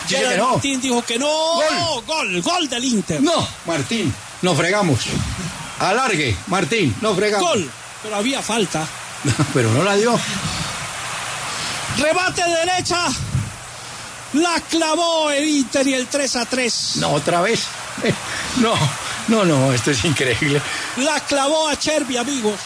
Martín de Francisco narró el gol de esperanza del Inter: “Le clavó el 3-3″
Así fue la narración de Martín de Francisco del gol del Inter: